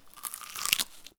bite.42ebb8b7363ccdd9efe8.wav